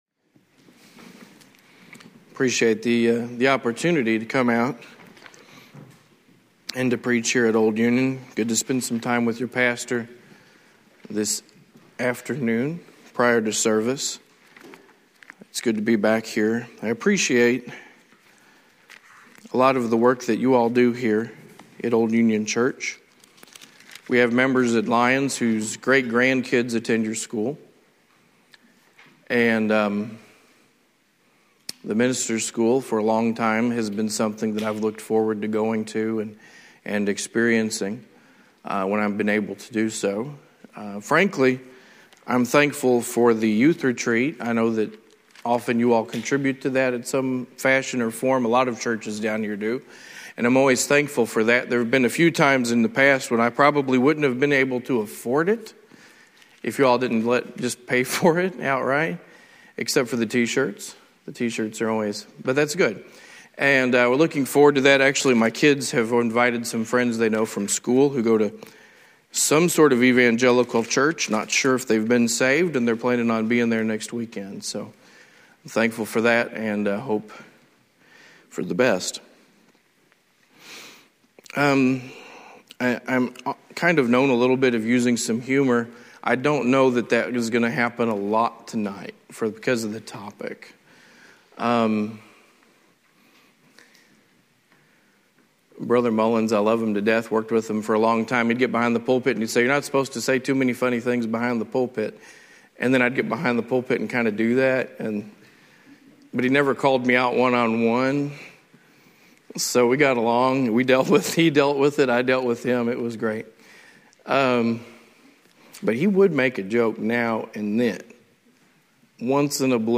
Lesson 1 from the 2009 Old Union Ministers School.
From Series: "Sunday Evening Sermons"